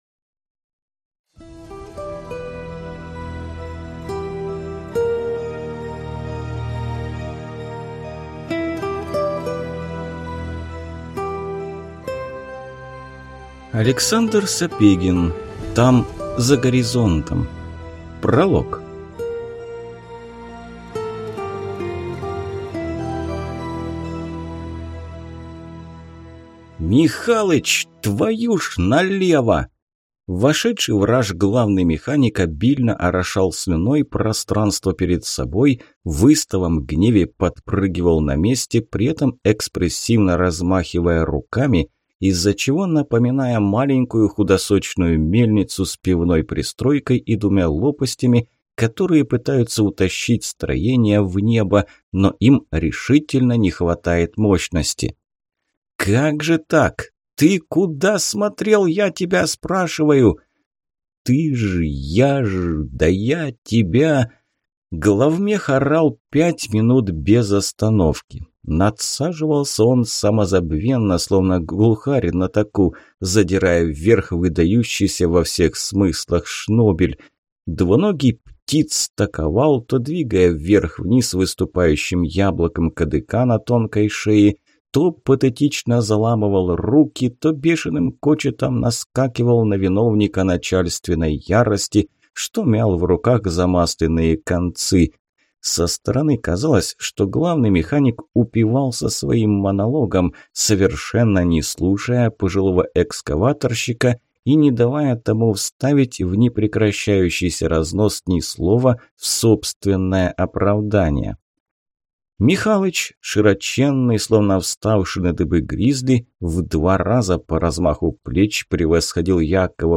Аудиокнига Там, за горизонтом | Библиотека аудиокниг